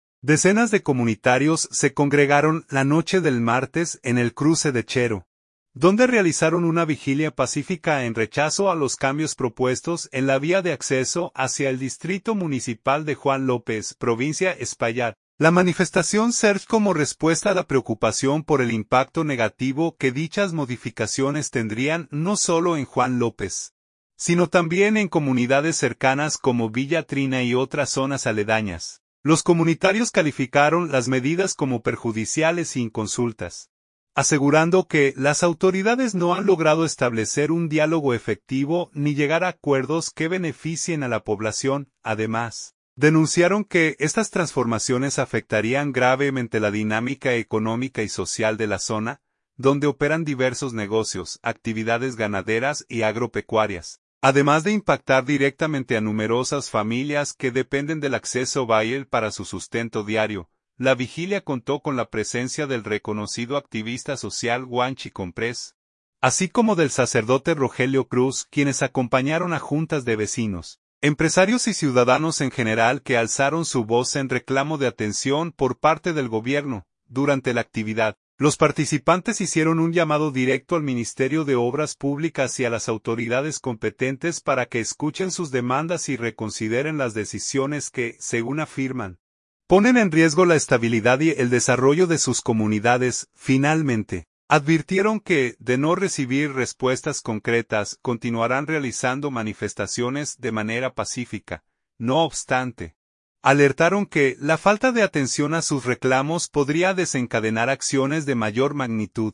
Decenas de comunitarios se congregaron la noche del martes en el Cruce de Chero, donde realizaron una vigilia pacífica en rechazo a los cambios propuestos en la vía de acceso hacia el distrito municipal de Juan López, provincia Espaillat.